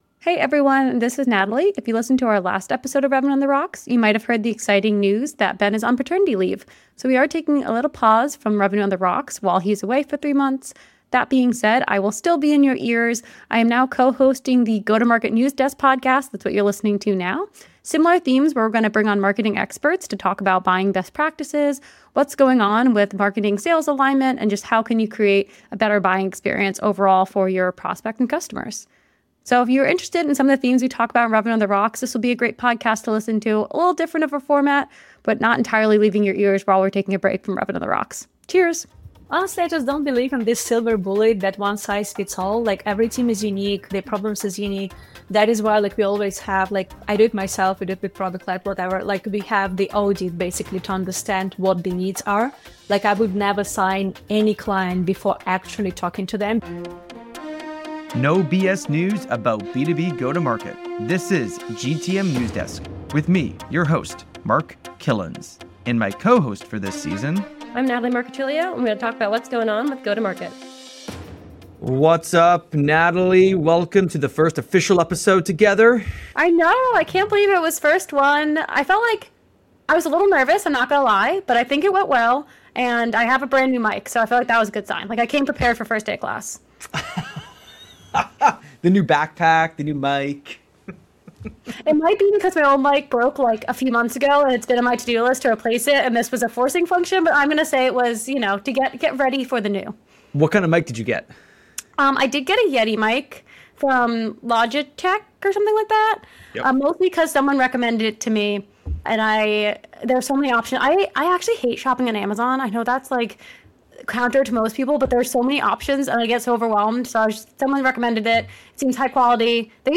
Instead of formal questions and answers, this podcast will be free-flowing and in-depth conversations about sales marketing and genuine stories from our career (all over a drink).